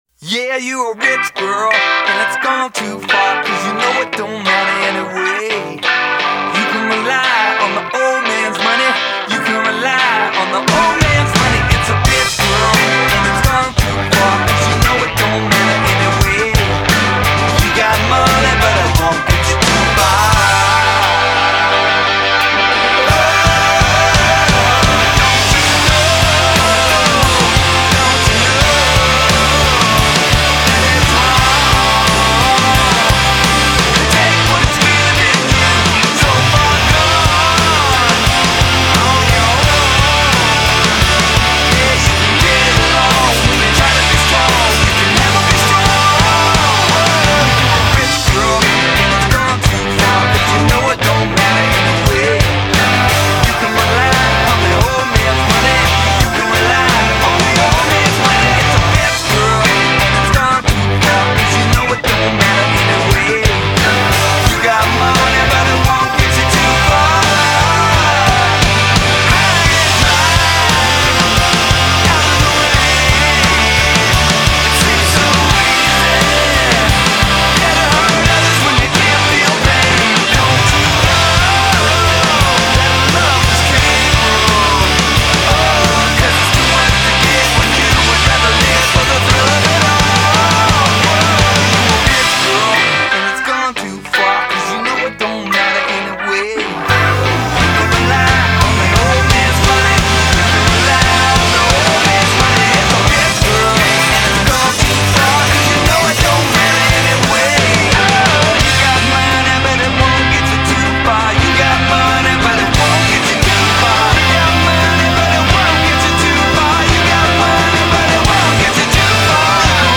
more recent grungy yet hooky take